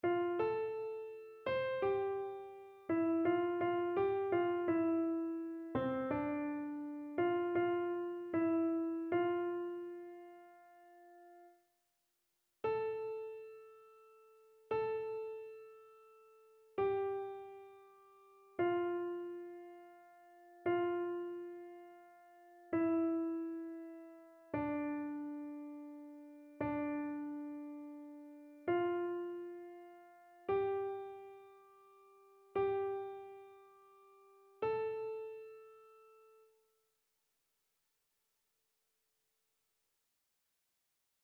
Soprano
annee-c-temps-pascal-5e-dimanche-psaume-144-soprano.mp3